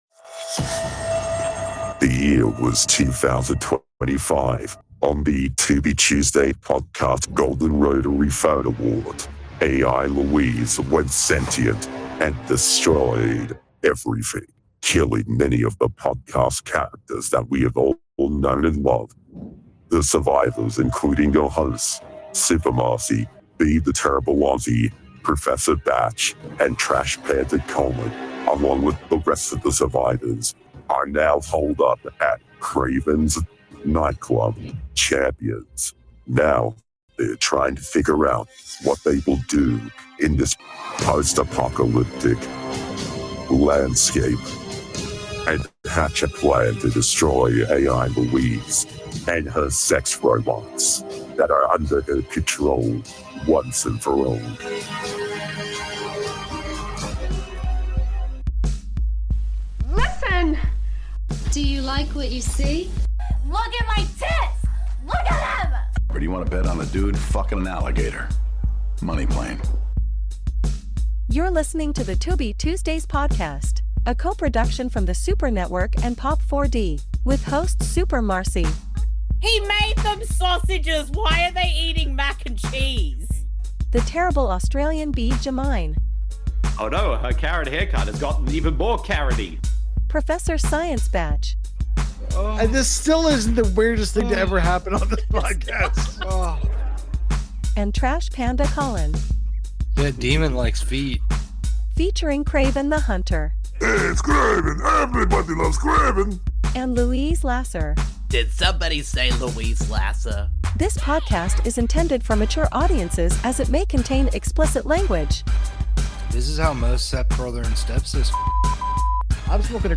Welcome back to The Tubi Tuesdays Podcast, the number 1 Tubi related podcast that’s hosted by two Australians, one Canadian and one American!